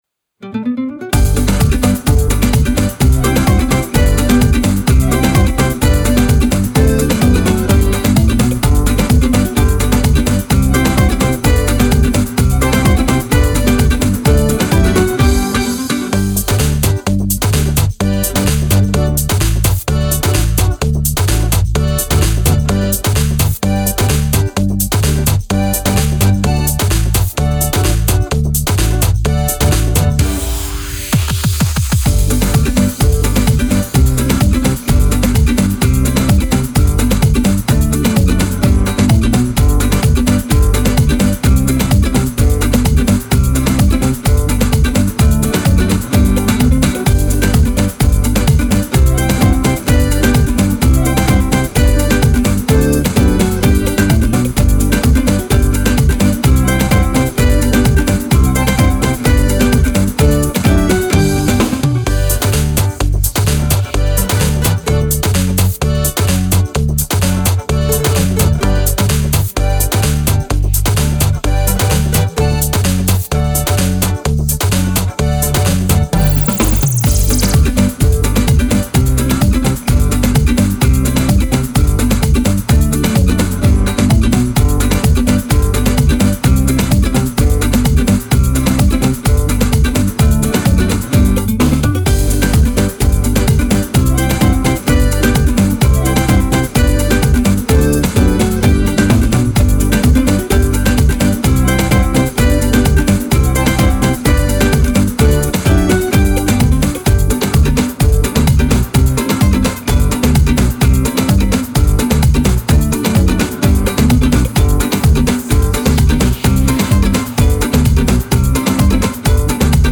a positive dance music with some guitar elements
positive
relax
latin
instrumental
dance
guitar
pop